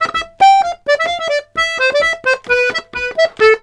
Index of /90_sSampleCDs/USB Soundscan vol.40 - Complete Accordions [AKAI] 1CD/Partition C/04-130POLKA
S130POLKA3-L.wav